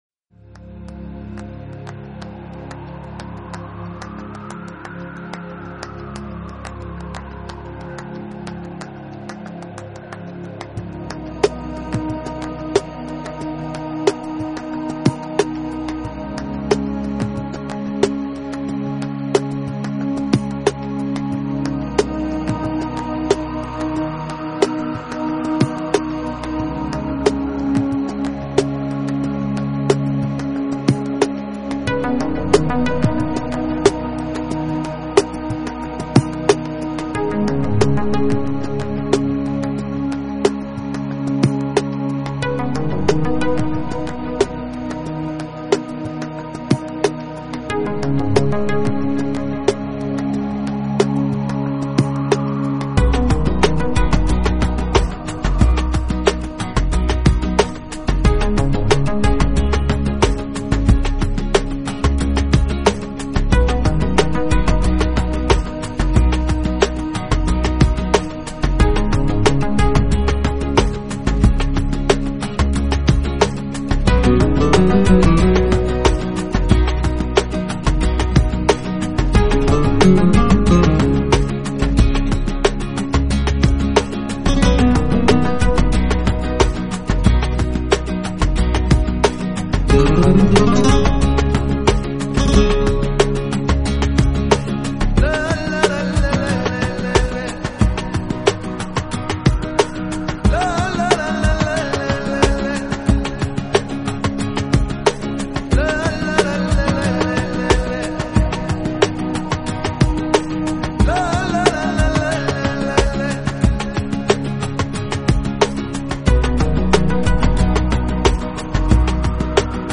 音乐给人带来温馨平和的气氛，也让现代忙碌的都会人带来一种舒缓、放鬆的效果，
旋律依旧是那闻名于世的佛拉明戈吉他，以及偶尔出现的佛拉明戈所独有的和声部
分，电子音乐的加入的确是淡化了些许佛拉明戈的激情，但也多了份非常放松的感